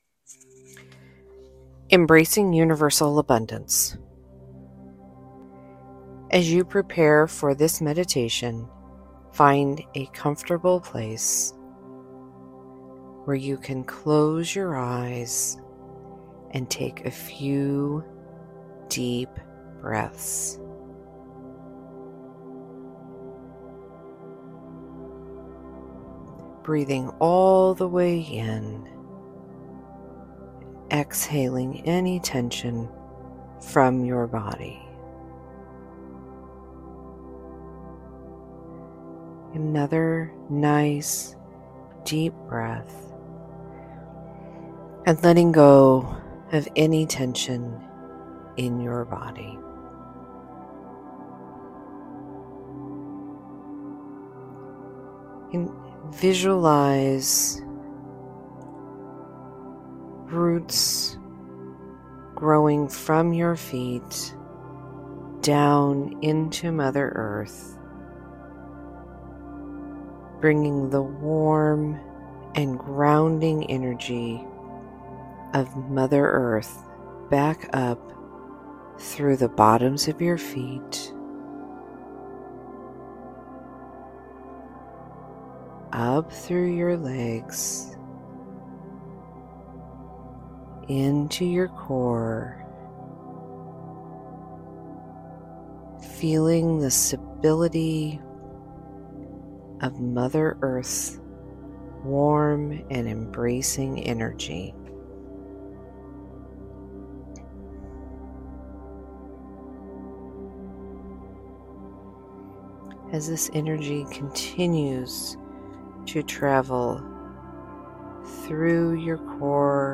Free Meditations for Stress, Sleep & Renewal